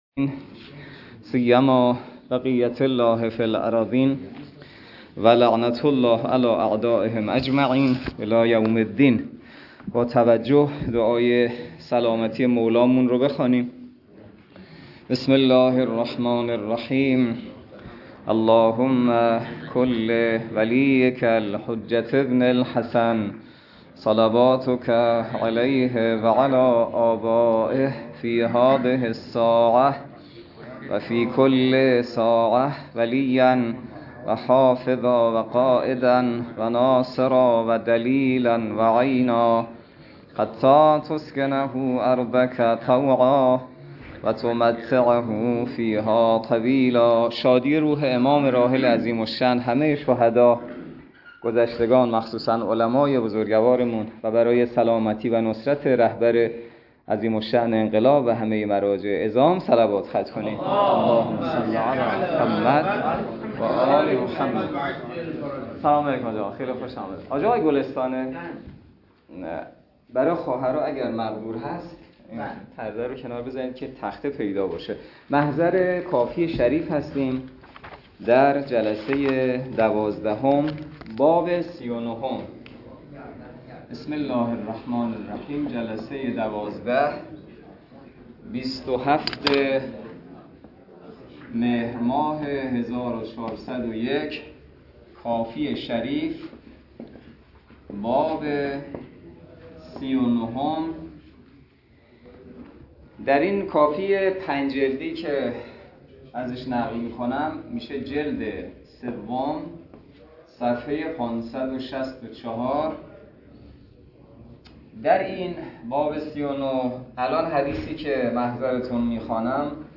درس فقه الاجاره نماینده مقام معظم رهبری در منطقه و امام جمعه کاشان - جلسه دوازدهم